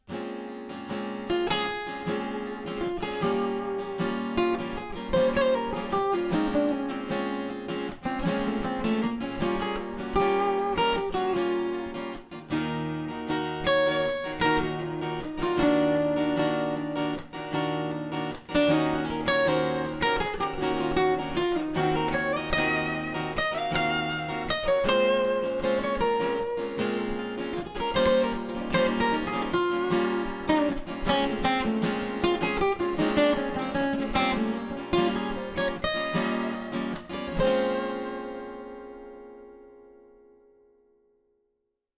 Guitare électro-acoustique :
Solo avec microphone externe et pickup interne :